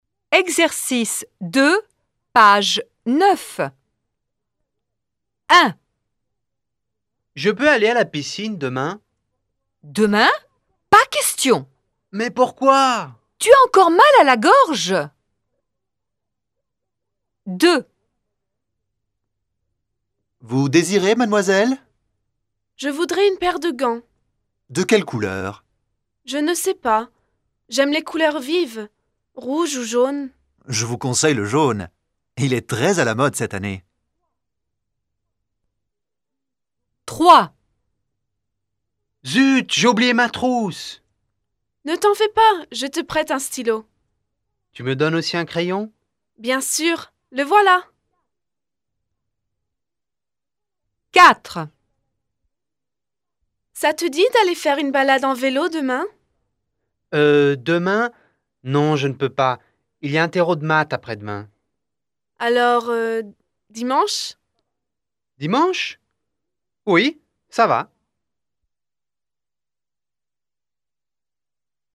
Ecoutez les 6 dialogues et dites à quelle image ils correspondent :